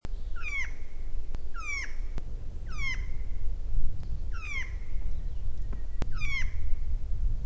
s3-1-2012mar26星鴉2.mp3
物種名稱 星鴉 Nucifraga caryocatactes owstoni
錄音地點 南投縣 信義鄉 塔塔加
錄音環境 雜木林
行為描述 鳥叫
收音: 廠牌 Sennheiser 型號 ME 67